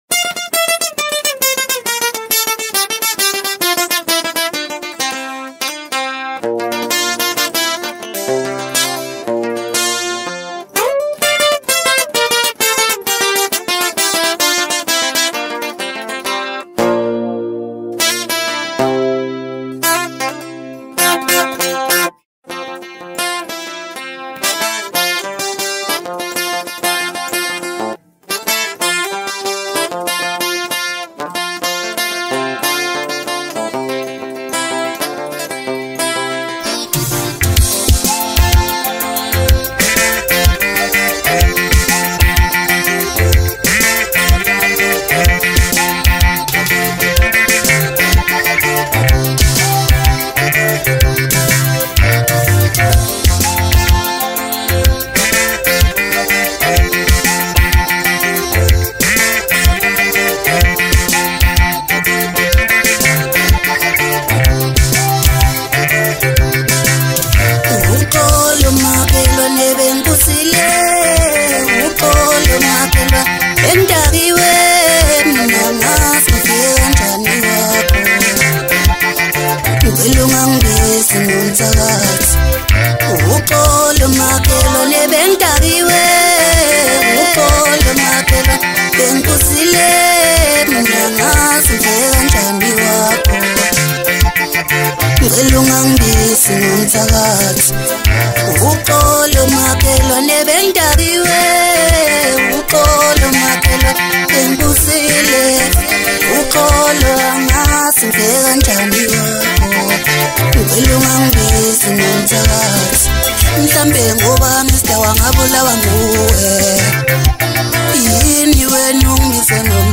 Maskandi